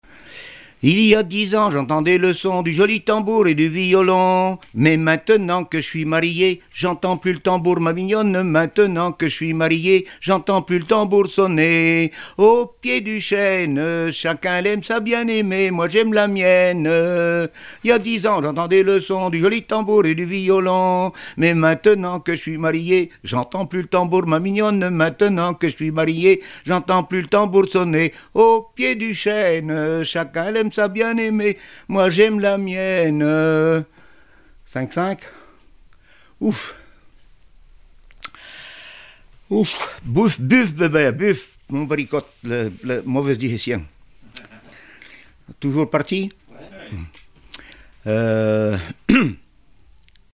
Fonction d'après l'informateur gestuel : à marcher ;
Genre énumérative
Répertoire de chansons populaires et traditionnelles
Pièce musicale inédite